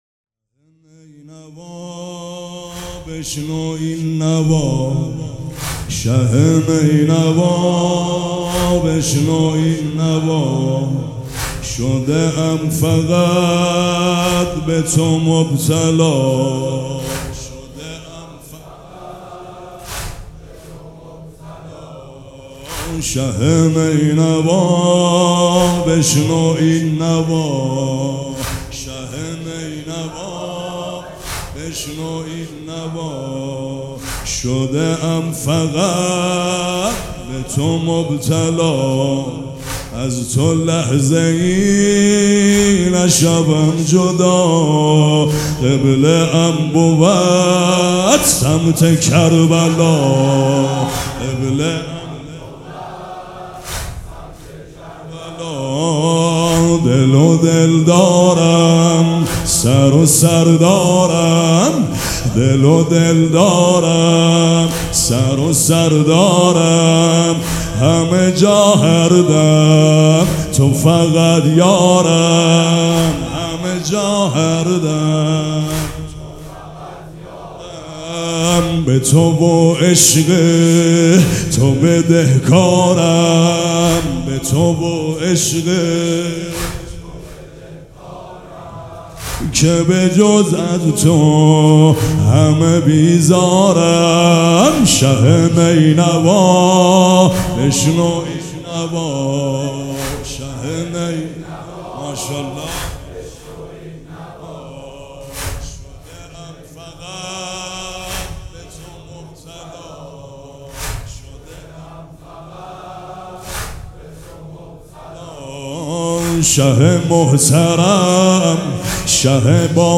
سبک اثــر واحد
مراسم عزاداری شب سوم